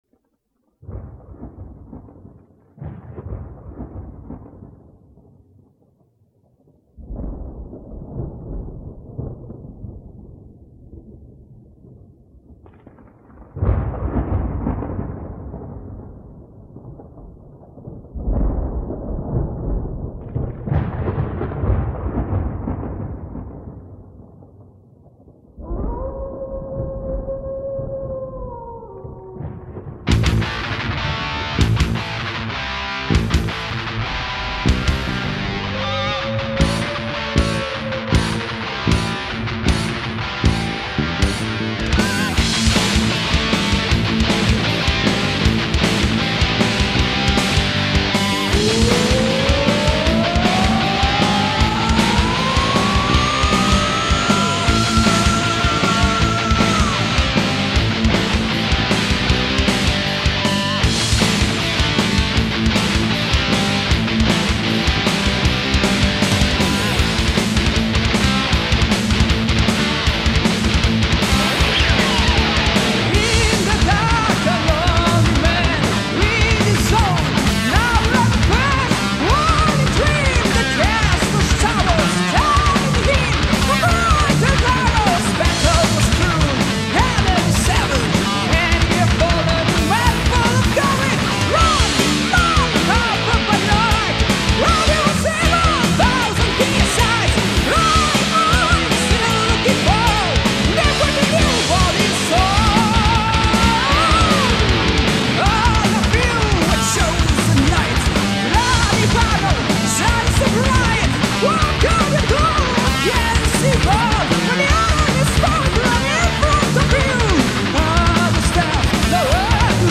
(demotape)
Guitar
Bass Guitar
Drums
Vocals